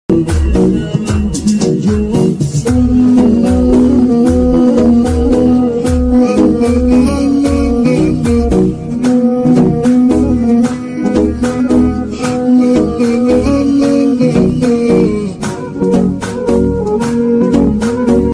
tiktok funny sound hahaha